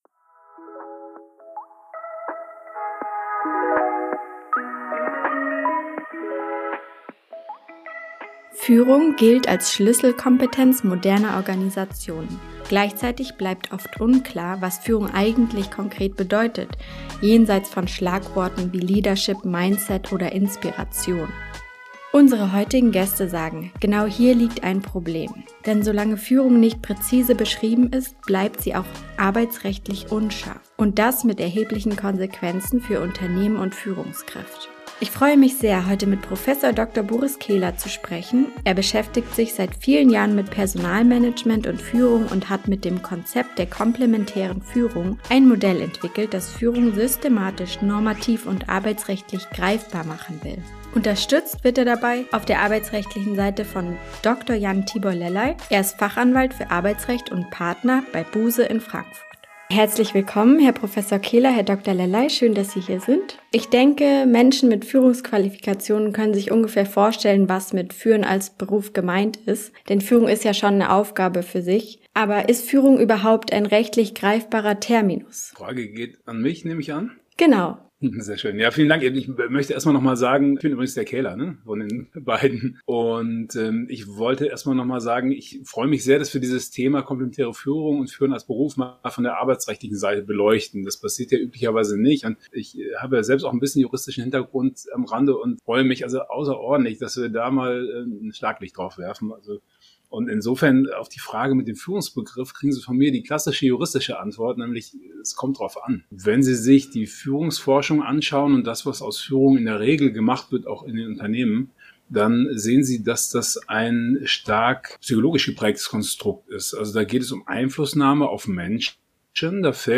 Ein Gespräch über Verantwortung, Klarheit – und die oft unterschätzte Komplexität moderner Führung.